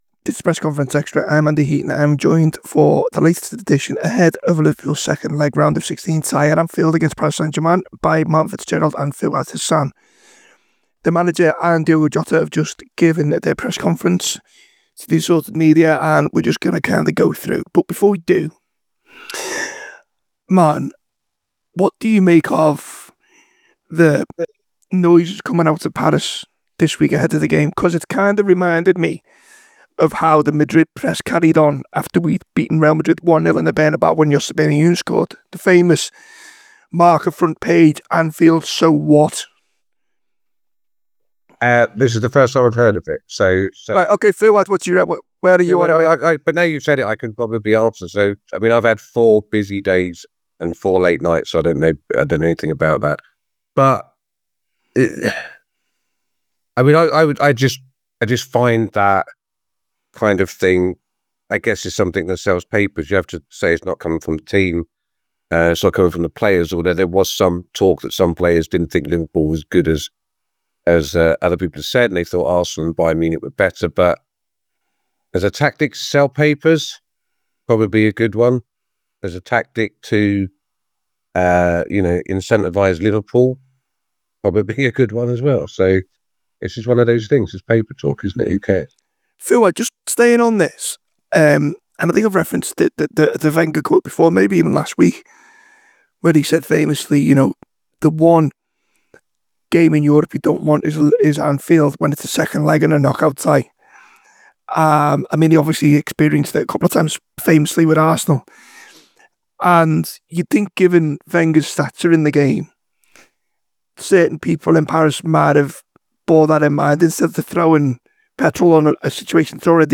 by | Mar 10, 2025 | app, Basic, Podcast, TAW Player, TAW Special | 0 comments